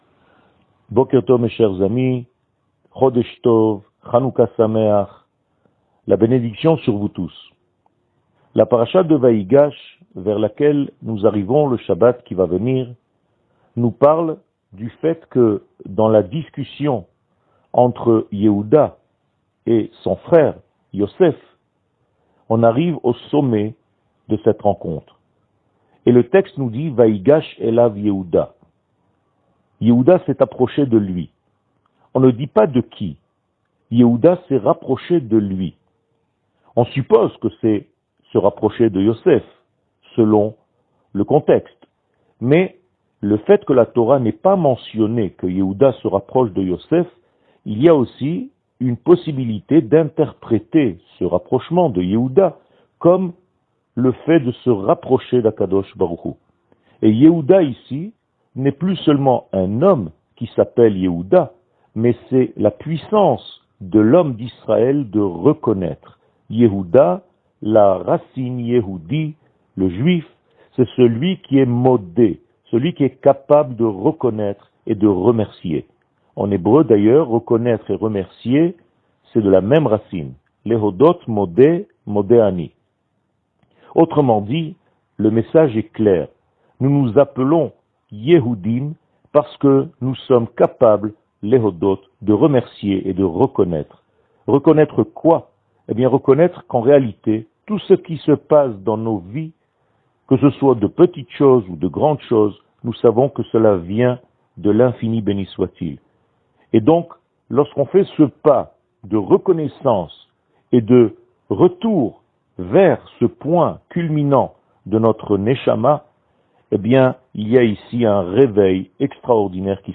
שיעור מ 07 דצמבר 2021
שיעורים קצרים